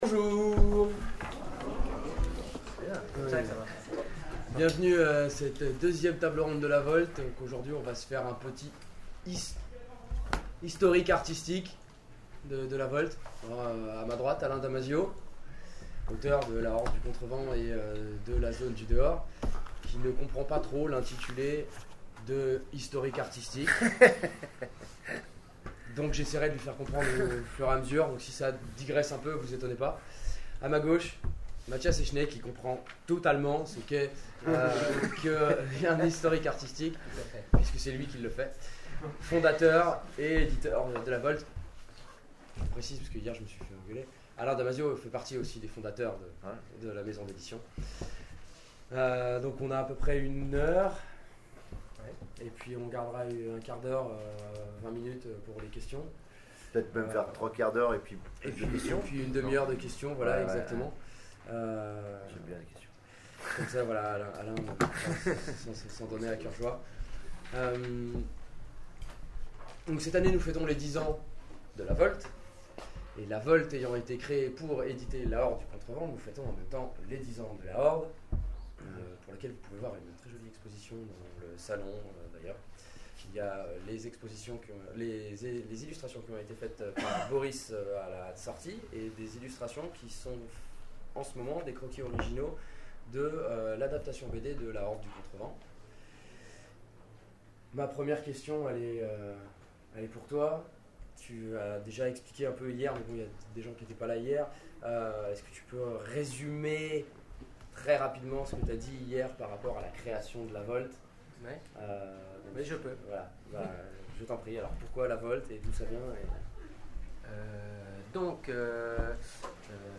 Les intergalactiques 2014 : conférence sur les 10 ans de la Volte